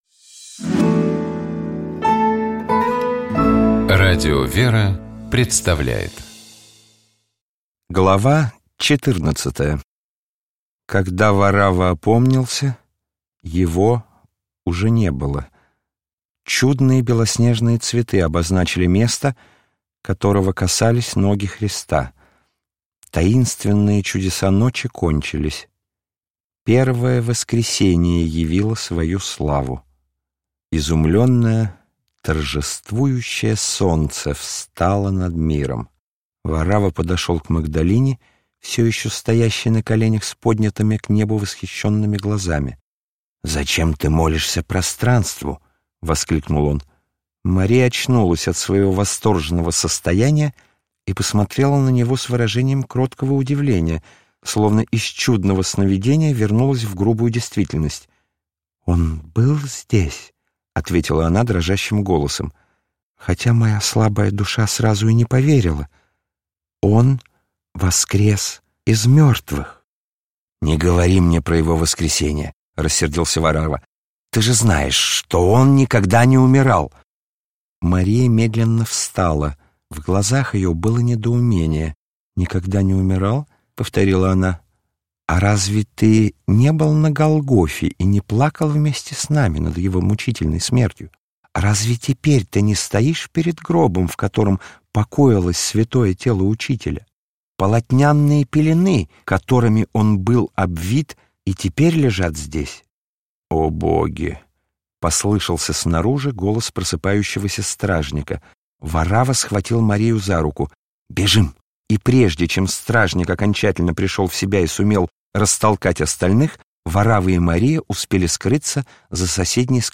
ГлавнаяАудиокнигиВаравва. Повесть времён Христа (М. Корелли)